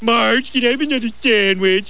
Esta es una lista con algunas voces que se oyeron en los episodios originales (en inglés) de los simpsons.